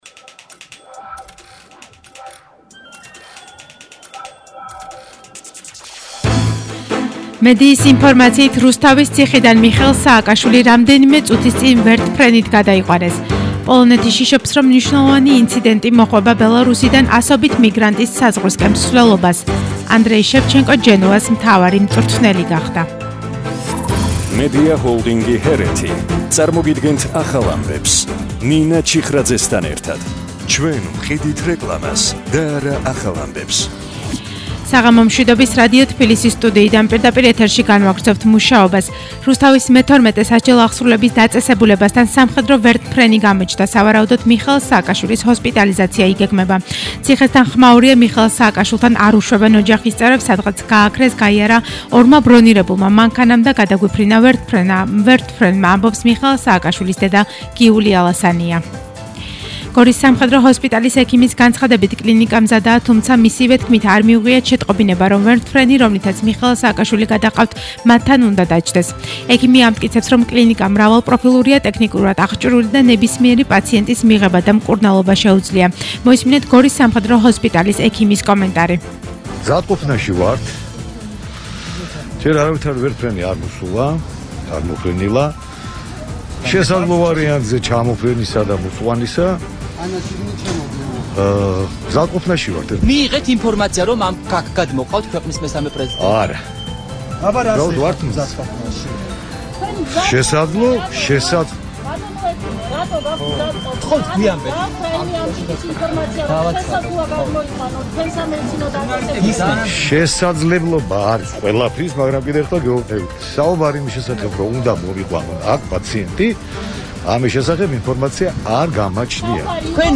ახალი ამბები 19:00 საათზე –8/11/21